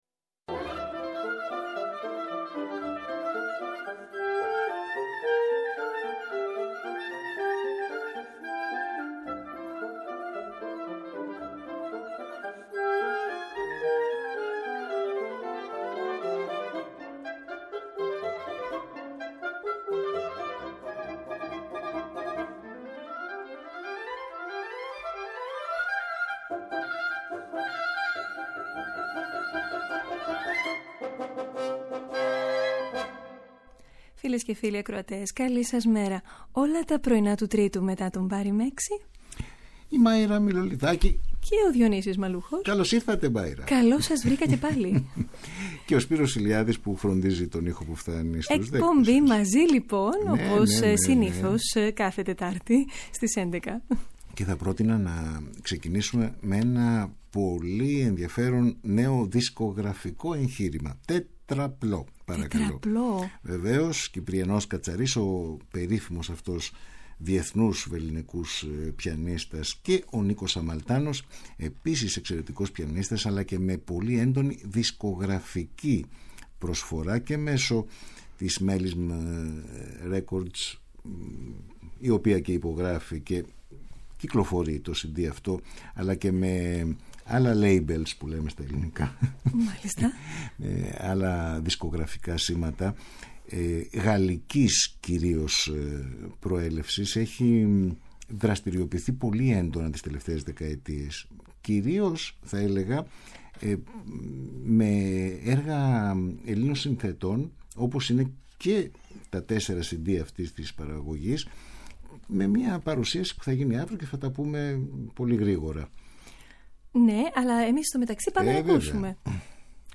Η πολυχρωμία της μουσικής ακρόασης μέσα από ένα ευρύ ρεπερτόριο έργων, με αφορμή την καλλιτεχνική κίνηση της εβδομάδας (συναυλίες, εκθέσεις και παραστάσεις, φεστιβάλ και εκδηλώσεις, νέες κυκλοφορίες ηχογραφήσεων και εκδόσεις.